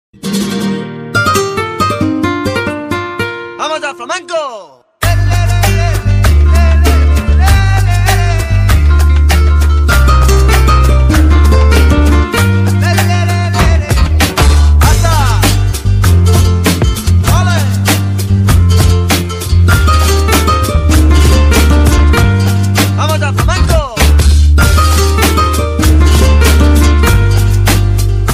. SMS hangok .
Latest_Nokia_Tone_V5.mp3